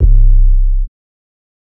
Southside 808 (8).wav